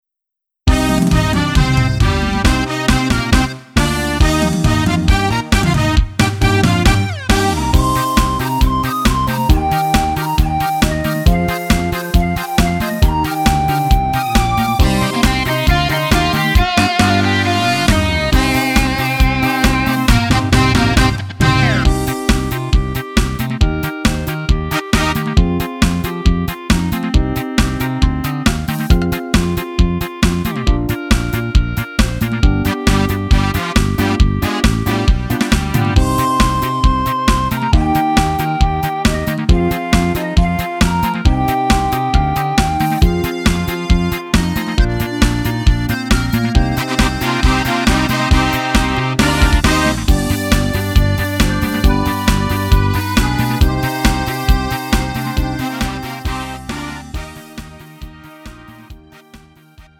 음정 원키 3:19
장르 구분 Lite MR